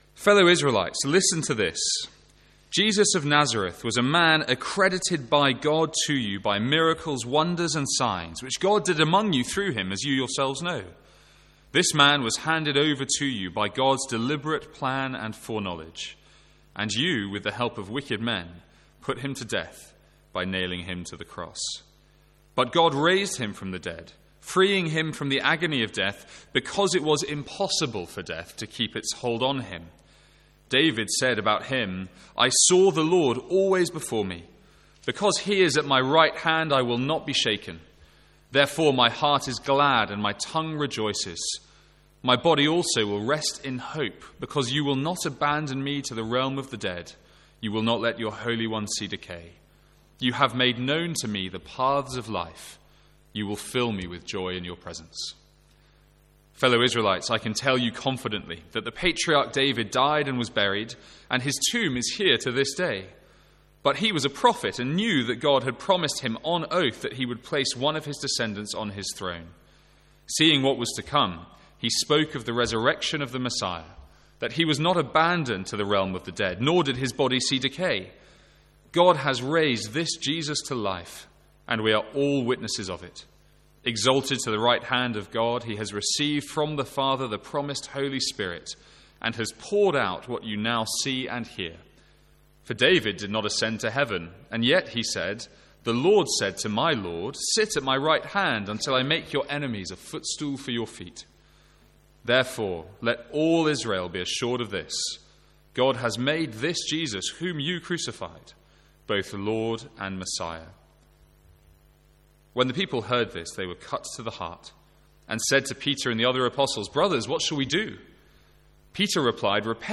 Sermons | St Andrews Free Church
From our Easter Sunday morning service.